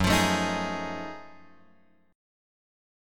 F#mM13 chord {2 x 3 2 4 4} chord